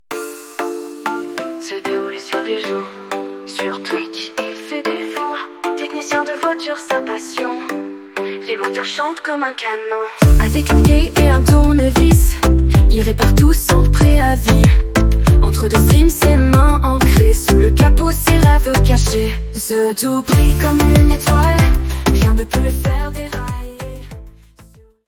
Style : Latino